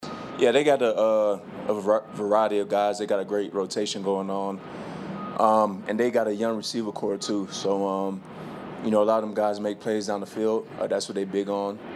Broncos cornerback Pat Surtain the Second on the challenge of facing a strong group of Green Bay receivers.